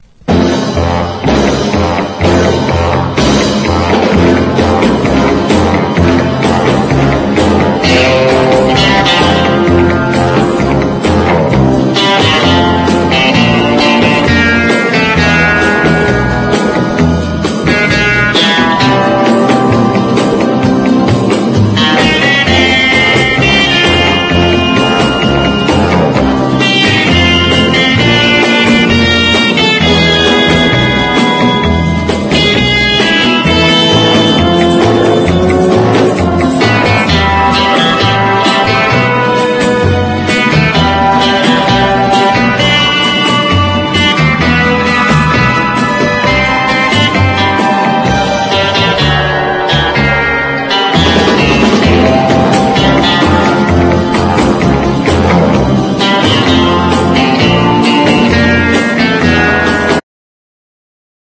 EASY LISTENING / OST
ヨーロピアンなグルーヴがすばらしいです！